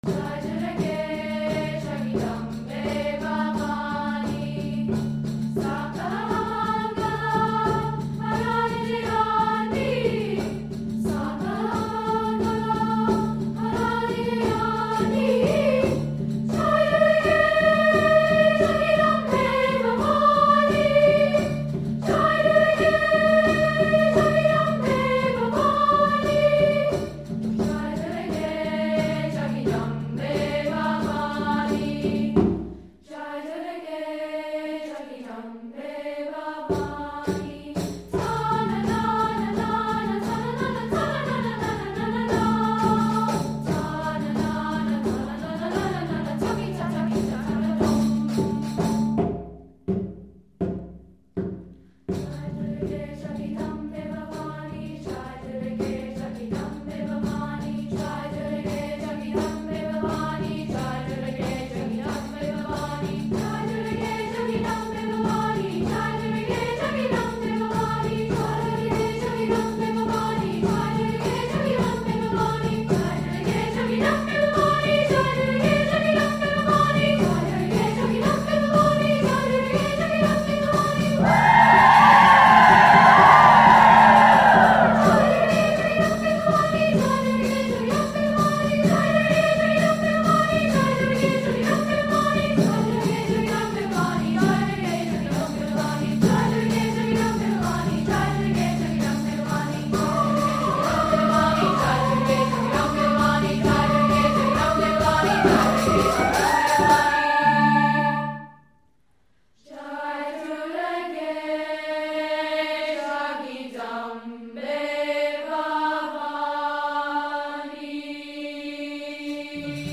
• Voicing: sa
• Accompaniment: percussion